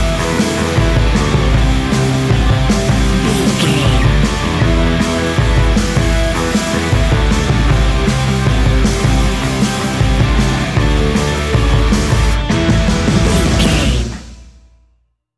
Ionian/Major
A♭
hard rock
heavy rock
distortion